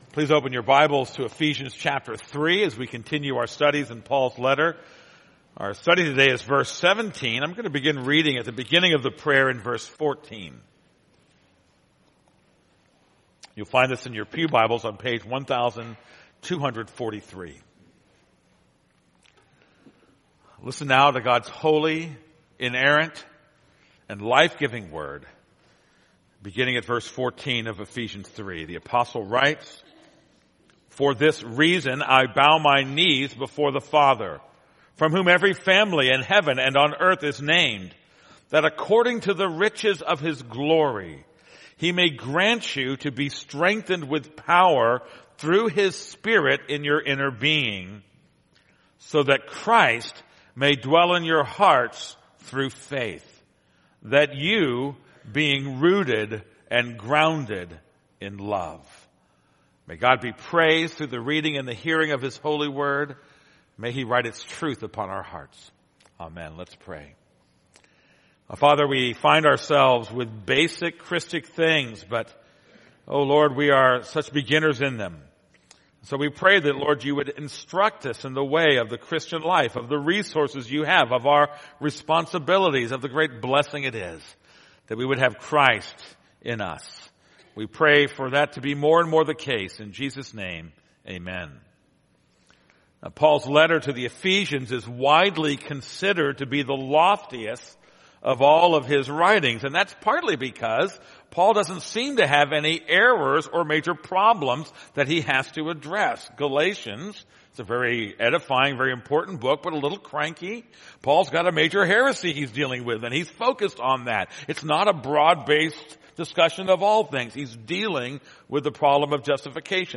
This is a sermon on Ephesians 3:17